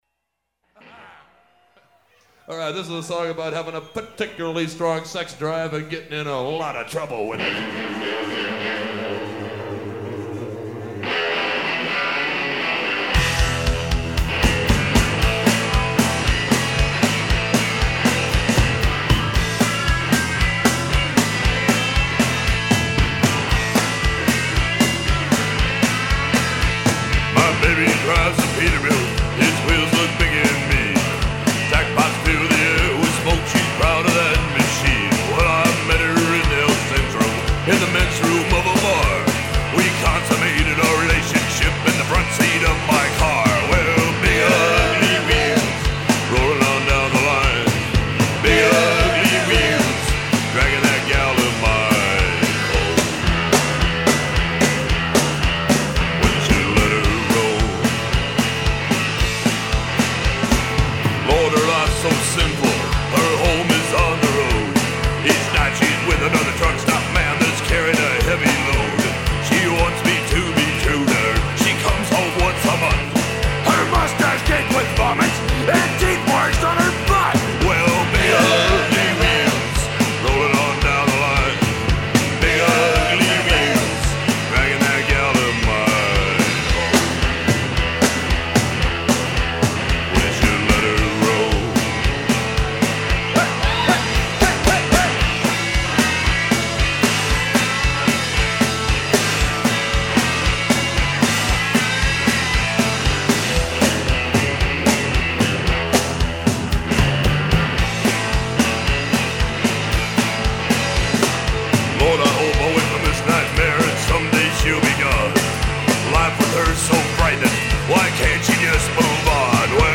were a great bar band from San Diego.